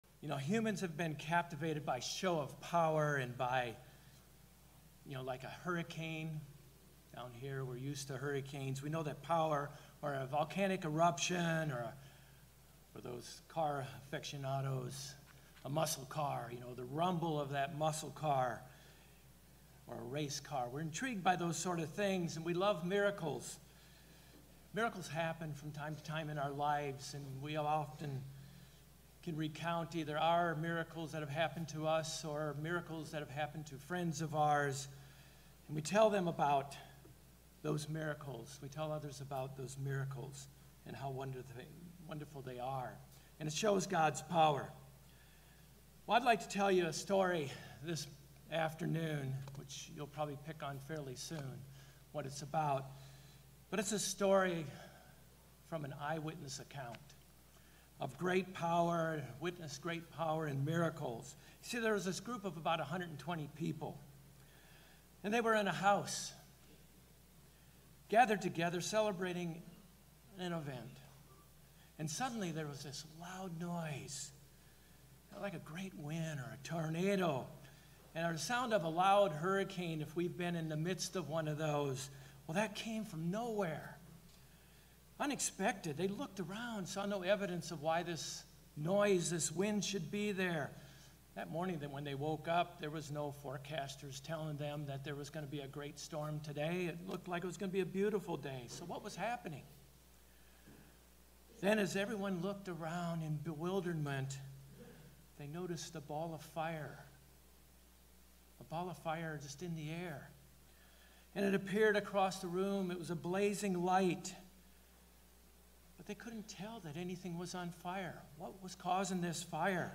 Pentecost is an important part of God’s Plan for Humankind’s salvation. In this sermon, we will discuss the first Pentecost along with four important lessons and meanings of Pentecost.